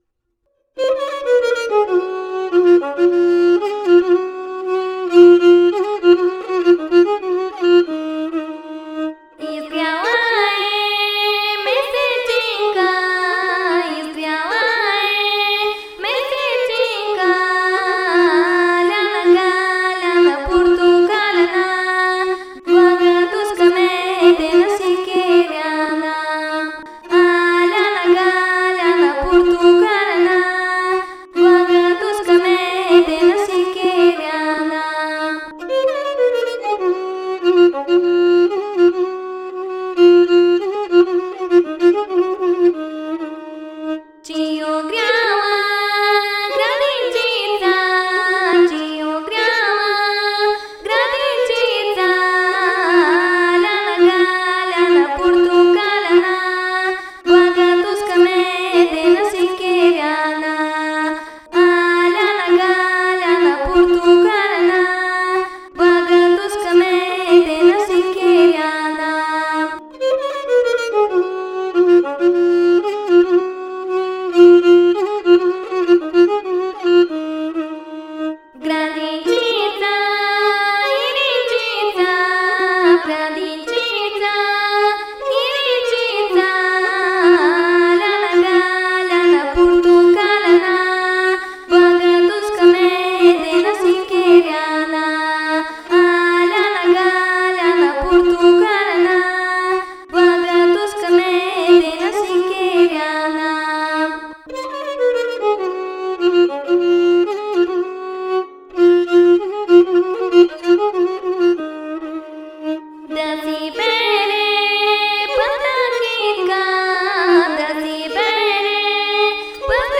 (e-moll, 7/8, 2:13)